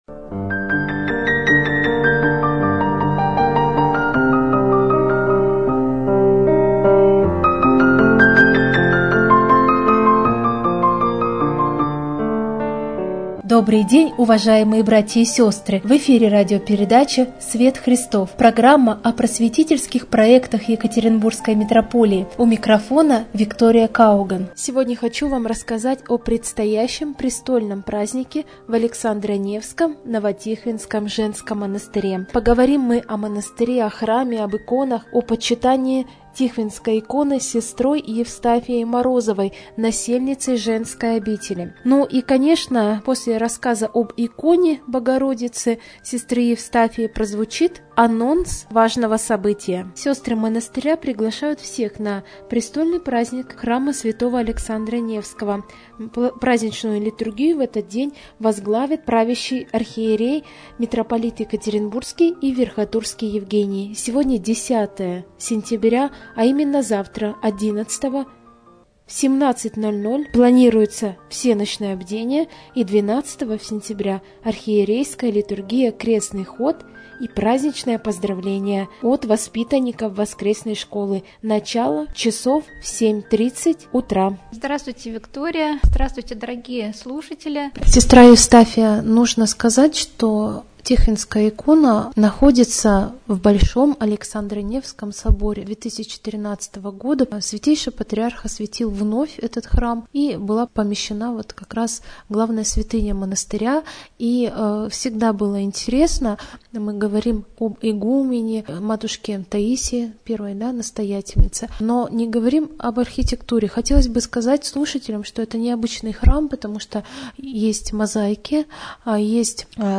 intervyu_o_novo_tihvinskom_monastyre_anons_arhierejskoj_liturgii_na_prestolnyj_prazdnik.mp3